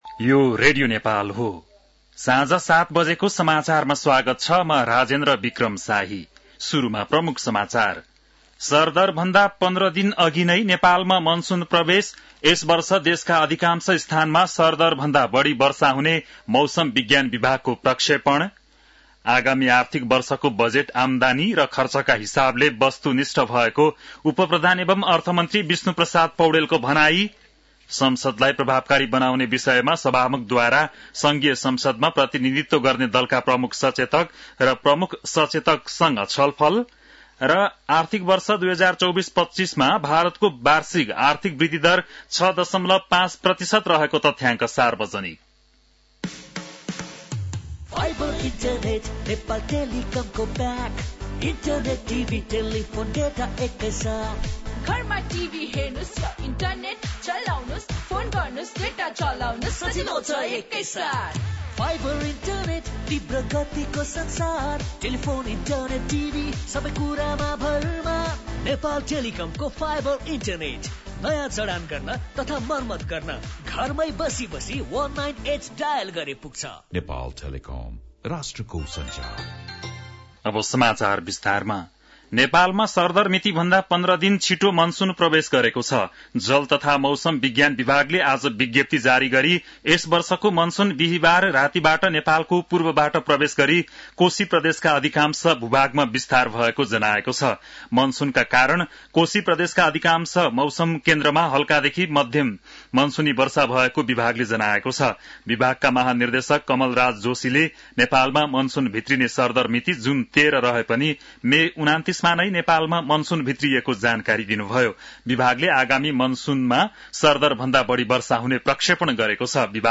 An online outlet of Nepal's national radio broadcaster
बेलुकी ७ बजेको नेपाली समाचार : १६ जेठ , २०८२
7-pm-nepali-news-2-16.mp3